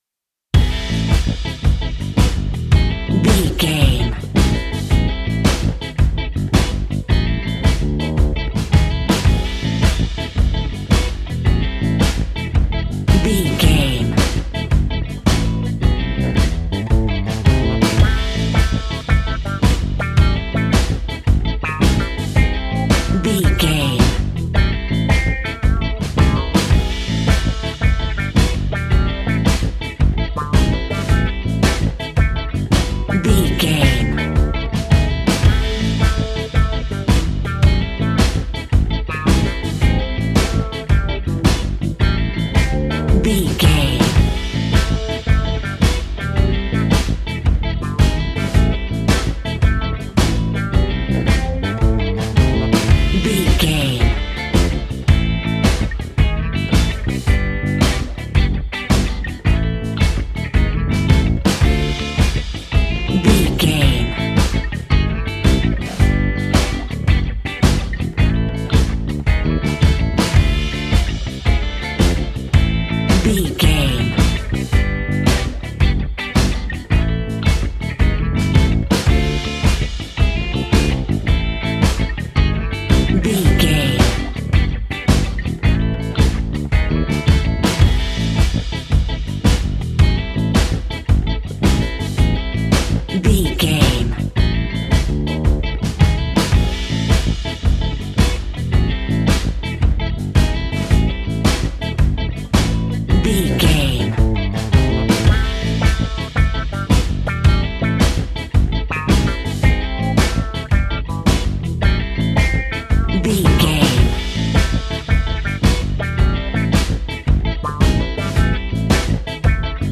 Aeolian/Minor
cool
funky
uplifting
bass guitar
electric guitar
organ
percussion
drums
saxophone
groovy